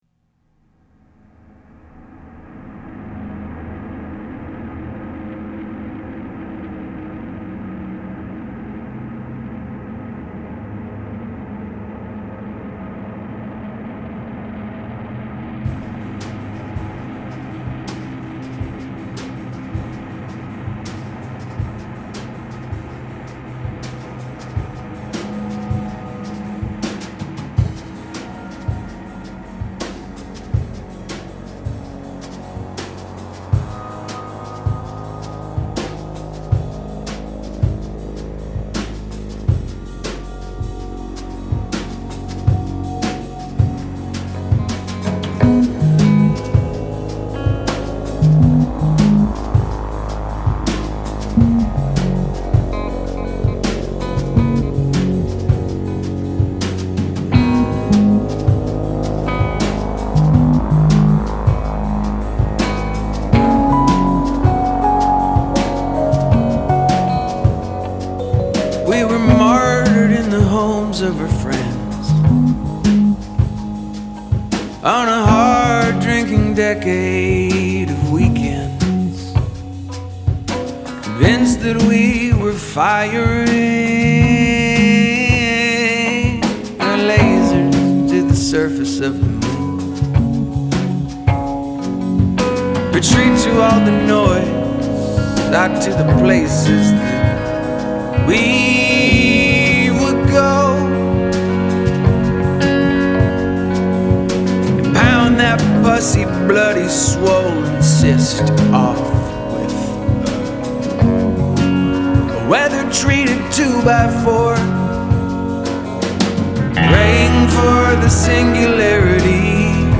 warm, dream-folk sound